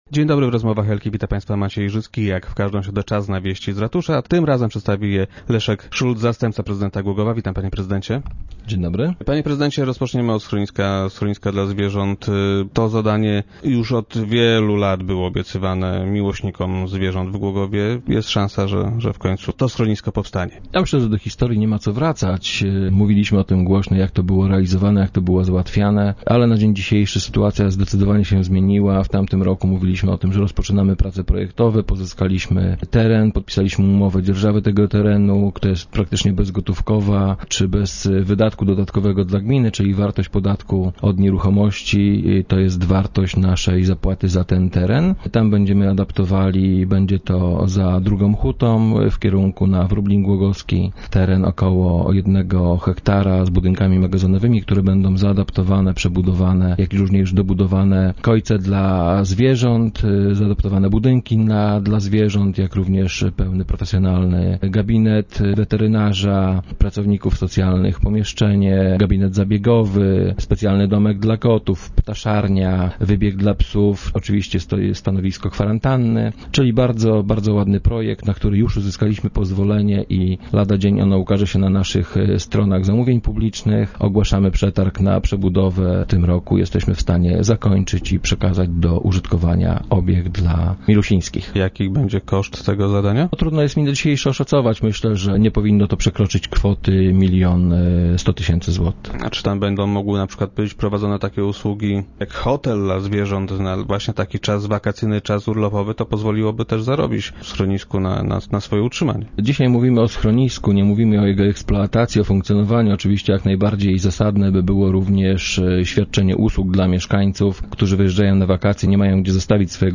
Jak powiedział dzisiejszy gość Rozmów Elki - wiceprezydent Leszek Szulc, lada dzień zostanie ogłoszony przetarg, który wyłoni firmę, która schronisko wybuduje.